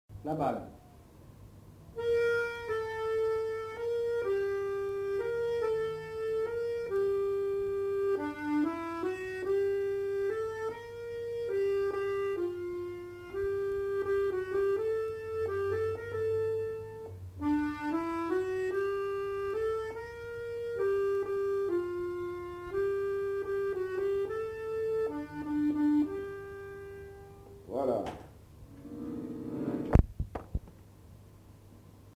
Aire culturelle : Gascogne
Genre : morceau instrumental
Instrument de musique : accordéon chromatique